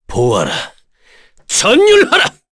Kain-Vox_Skill2_kr.wav